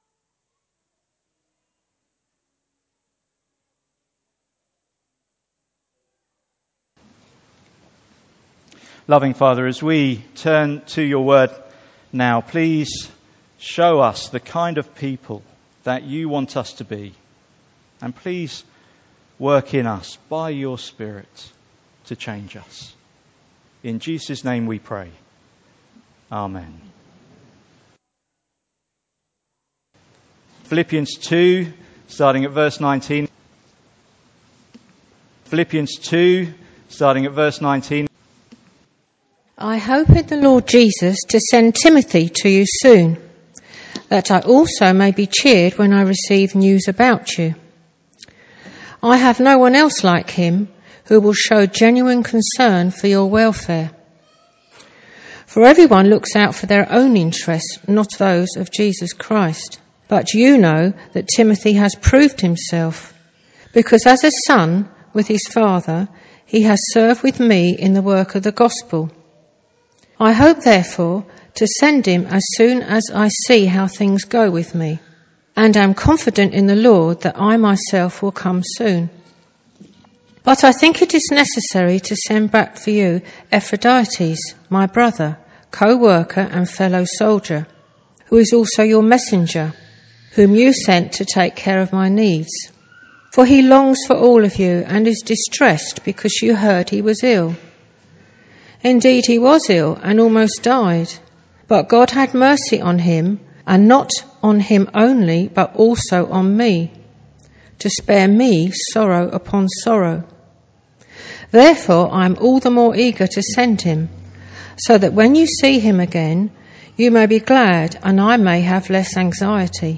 Passage: Philippians 2:19-30 Service Type: Sunday Morning